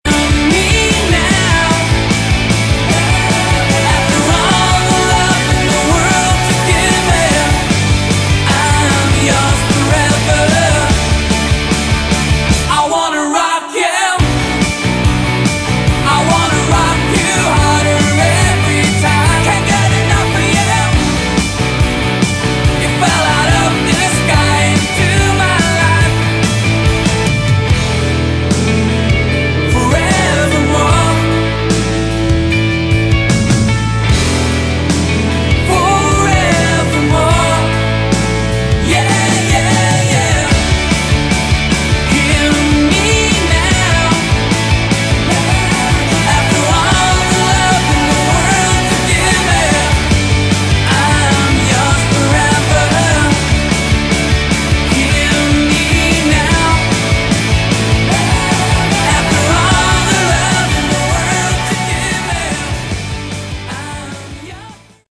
Category: Melodic Rock
Bass
Lead Vocals, Guitar
Drums
Keyboards